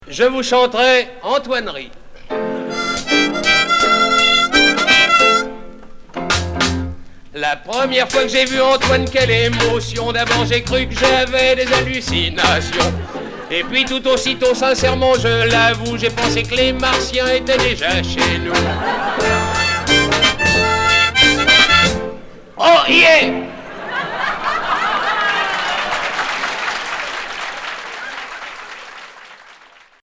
Chanteur 60's 45t pastiche d' Antoine retour à l'accueil